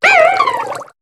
Cri de Viskuse dans Pokémon HOME.